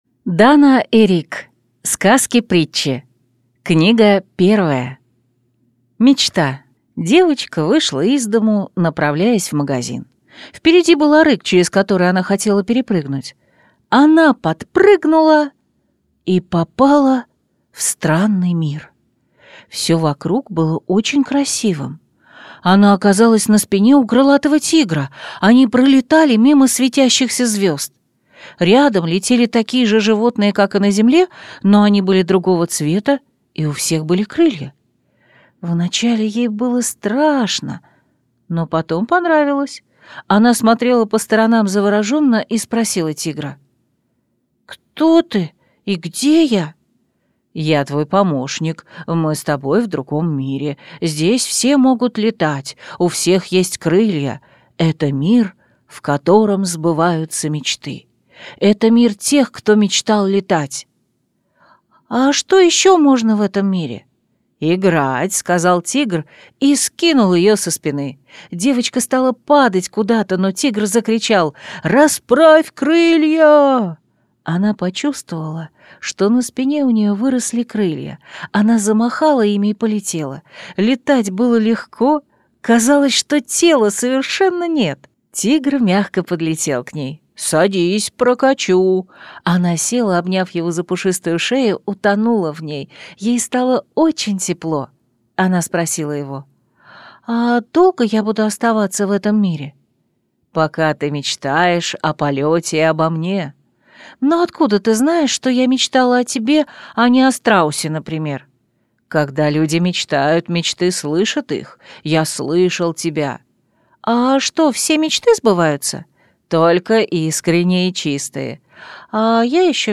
Аудиокнига Сказки-притчи. Книга 1 | Библиотека аудиокниг